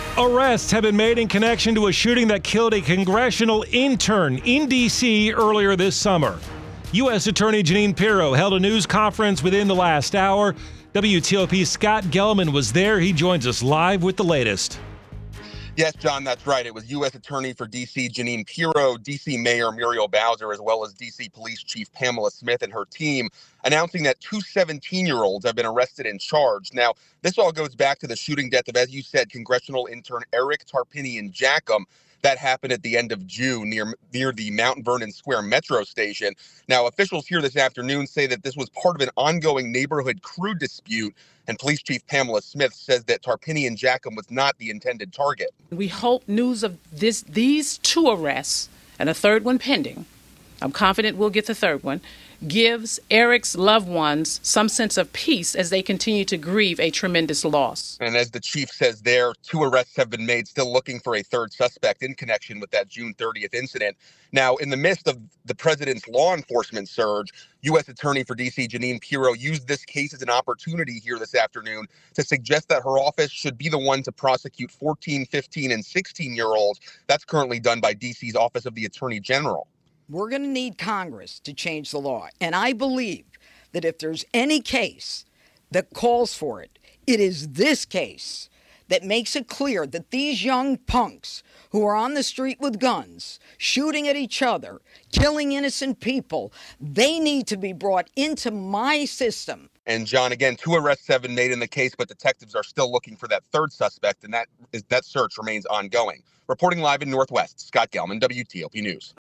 reported live on the arrests.